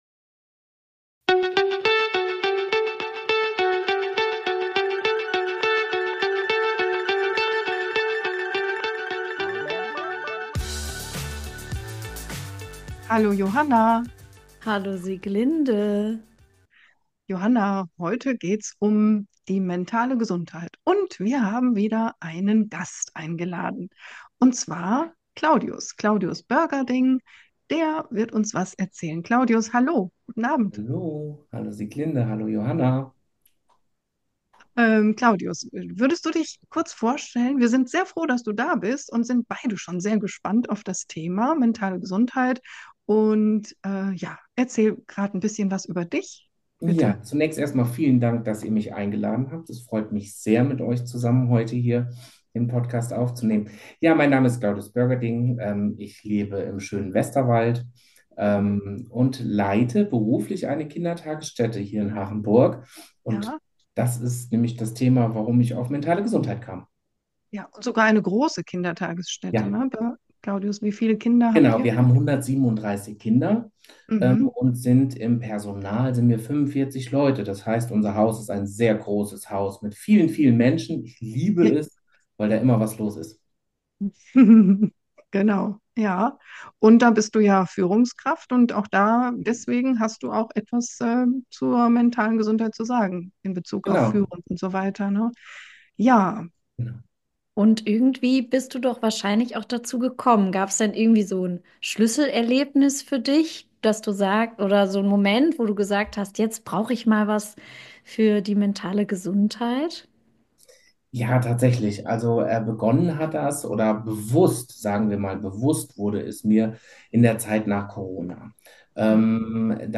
Unser Interviewgast